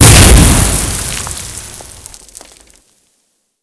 GrenExpl04.wav